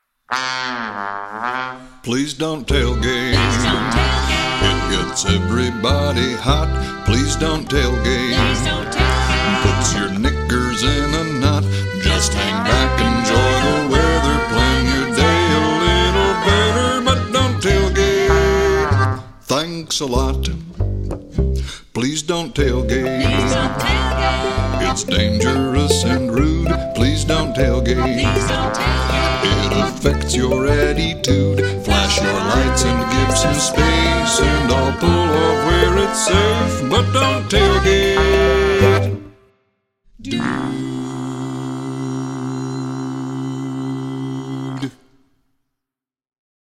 vocals, guitar
trombone
button accordion
bass
mandolin